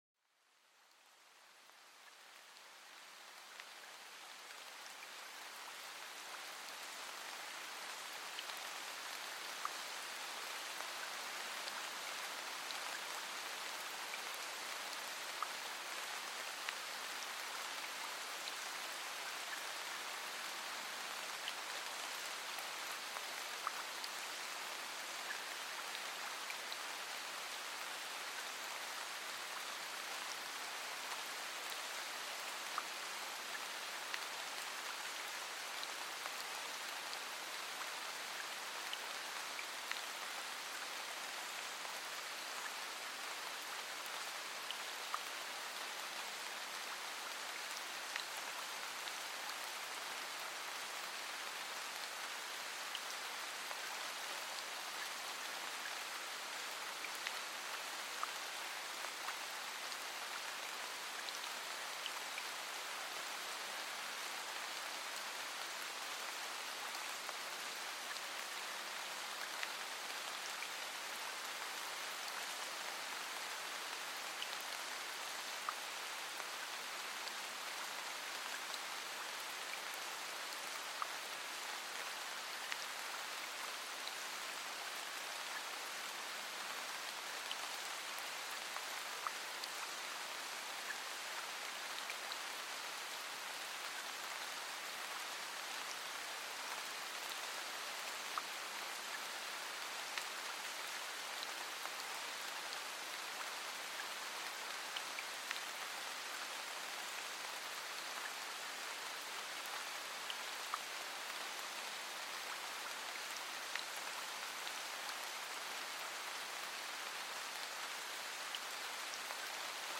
Relajación con Lluvia Suave para un Sueño Reparador
Sumérgete en la atmósfera tranquilizante de una suave lluvia cayendo delicadamente. Cada gota crea una melodía relajante, perfecta para desconectar y dormir.